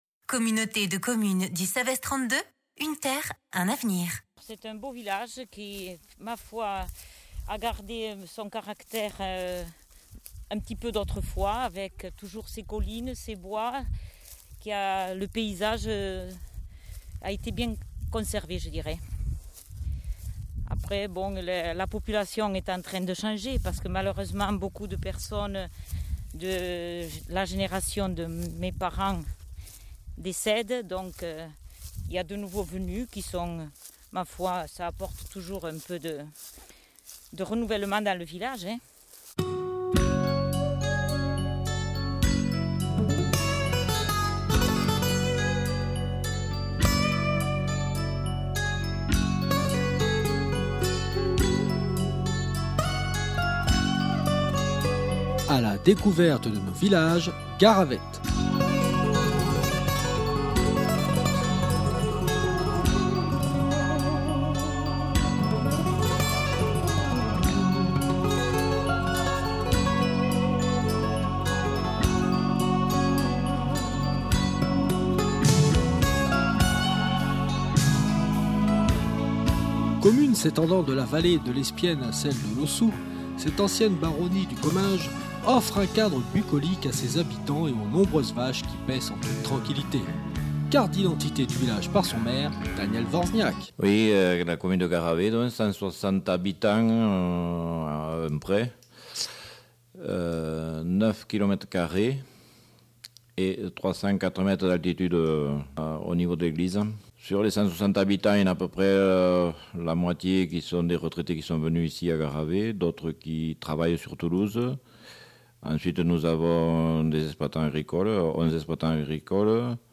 Reportage sonore